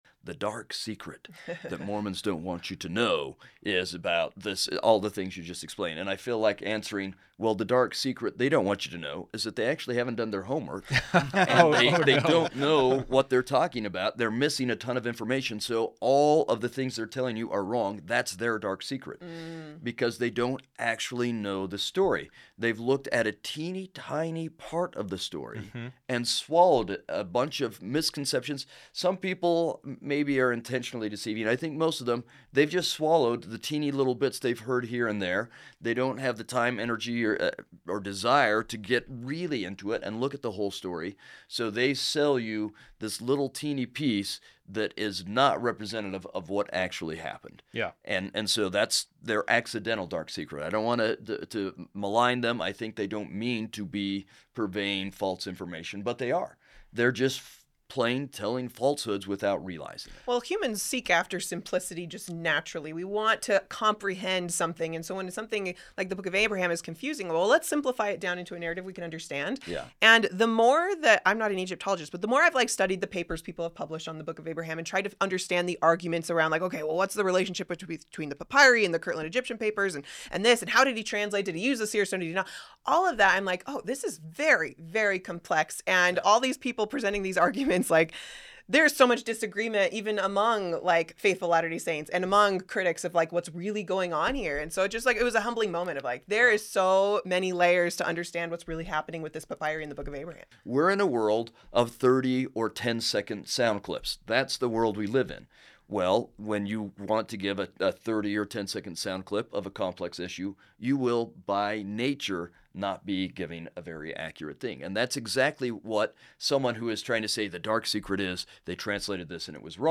In this conversation, we slow down and add the missing context: what the recovered fragments actually are, why the assumption that the adjacent text must equal the Book of Abraham text is questionable, and what multiple eyewitness accounts say Joseph Smith was translating from.